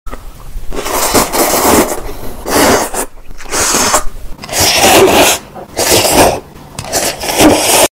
Random color food mukbang Korean sound effects free download
Random color food mukbang Korean ASMR Testing mukbang Eating Sounds Shorts Sounds